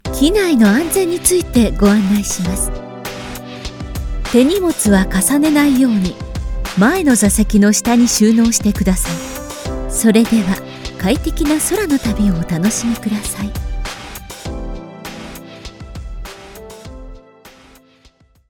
Ideal for corporate narration, IVR, animation, and commercials, she delivers professional voice over services with broadcast-level clarity and speed.
Explainer Videos